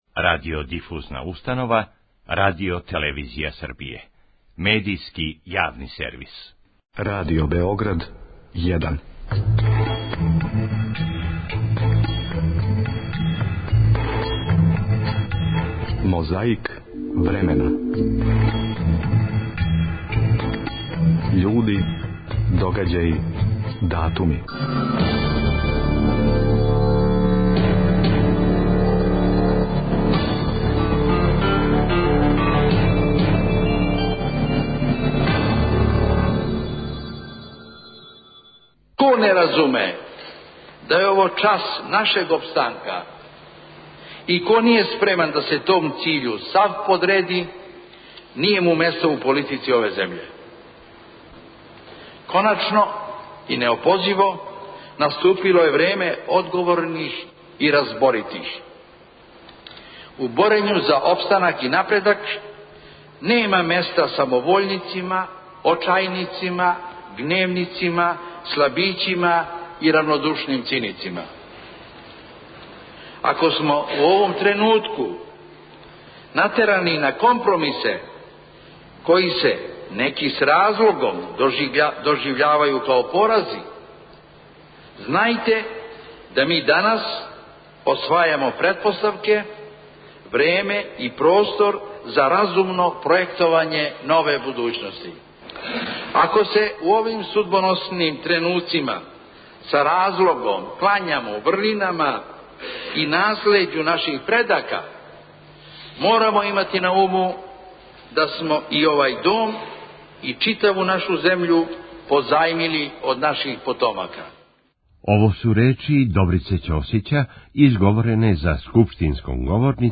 Na dan svog punoletstva, 6. septembra 1941. godine, kralj Petar II Karađorđević poslao je iz Londona radio poruku narodu Jugoslavije.
Подсећа на прошлост (културну, историјску, политичку, спортску и сваку другу) уз помоћ материјала из Тонског архива, Документације и библиотеке Радио Београда. Свака коцкица Мозаика је један датум из прошлости.